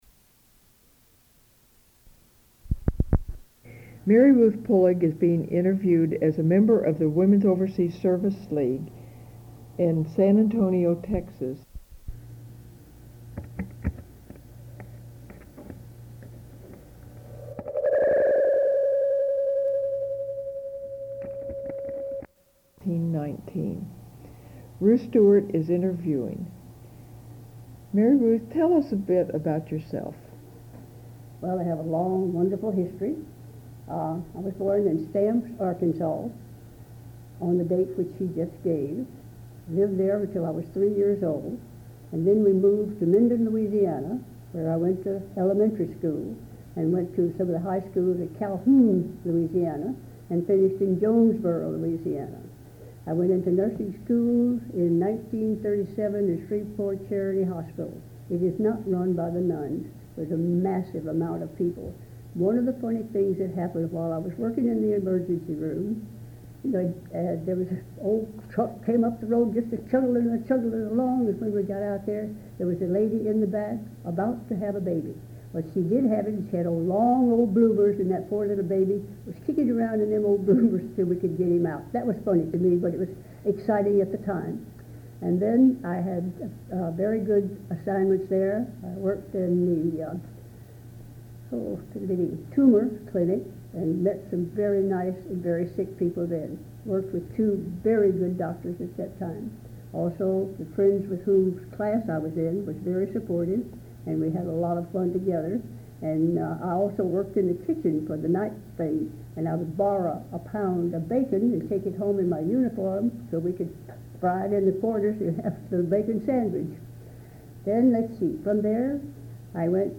Interview
Sound recordings Interviews